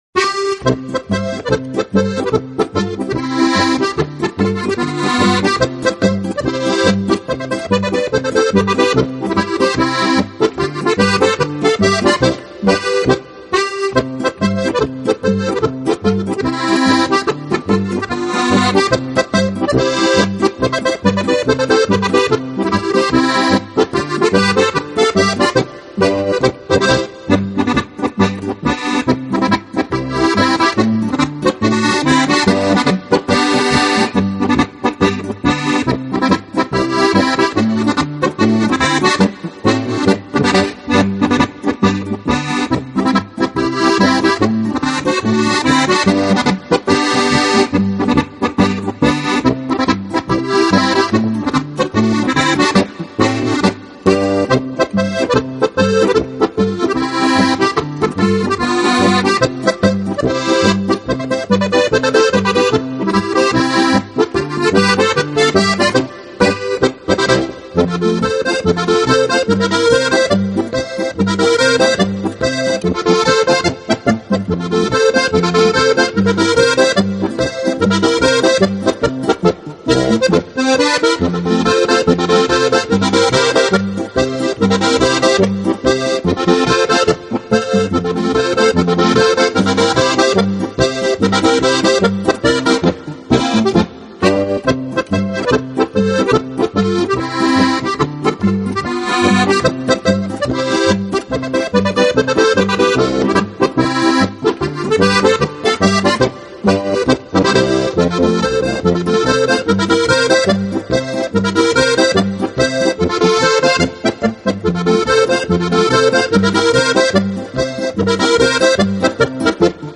【轻音乐】手风琴、口琴与波尔卡音乐集锦